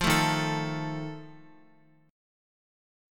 D#sus2b5 chord